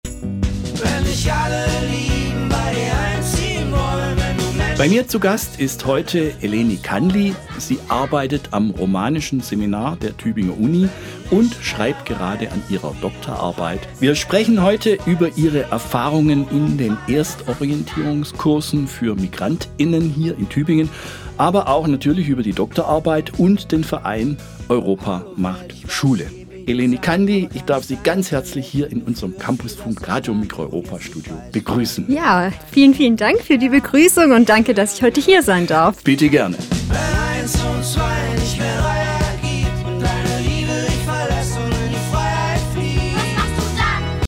Teaser_540.mp3